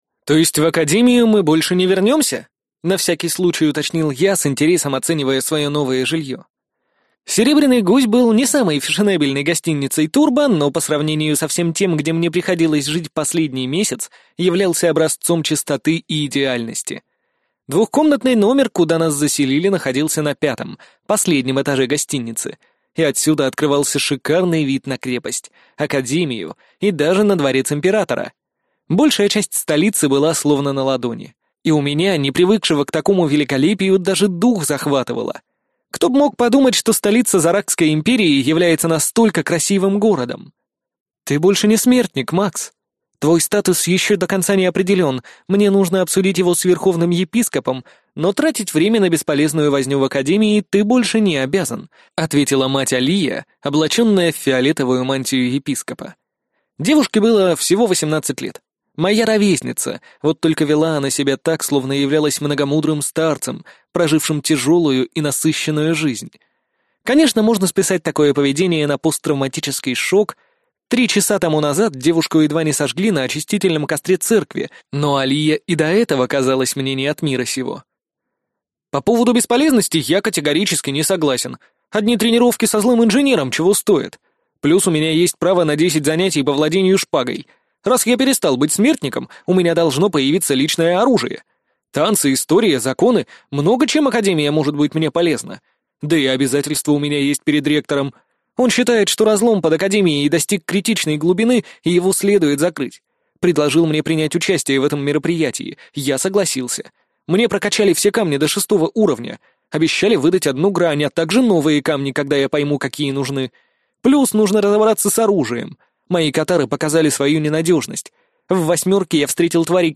Аудиокнига Смертник из рода Валевских. Книга 3 | Библиотека аудиокниг